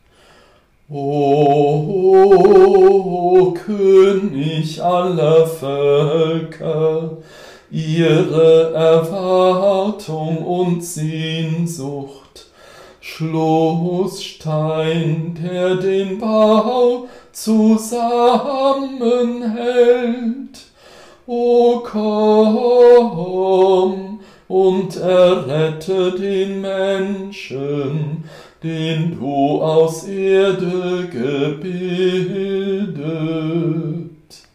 Antiphon